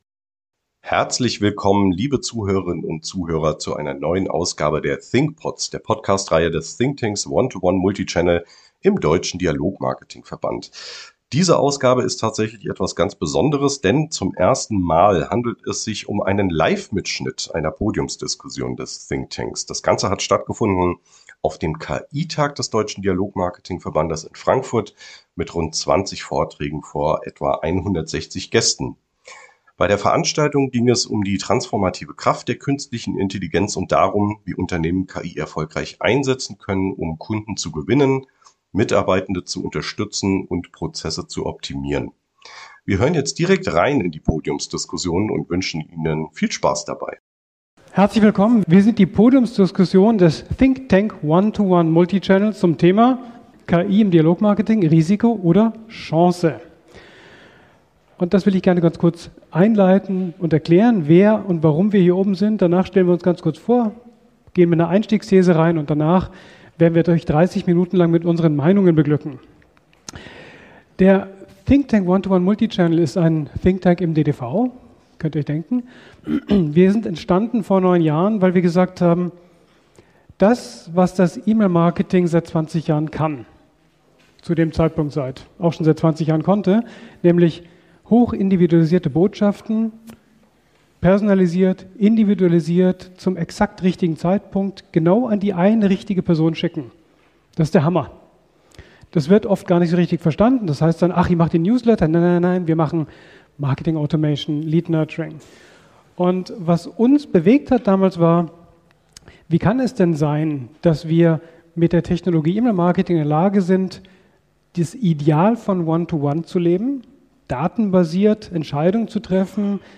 Beschreibung vor 1 Jahr Die neue Ausgabe der Thinkpods ist ein Live-Mitschnitt der Podiumsdiskussion des ThinkTank ONEtoONE Multichannel auf dem KI-Tag des DDV am 13. November in Frankfurt. In ihrem rund 30minütigen Austausch beleuchten die Mitglieder des ThinkTanks nach einem kurzen Intro samt Vorstellungsrunde das Potenzial der KI-Technologie aus Unternehmens- und Kundensicht.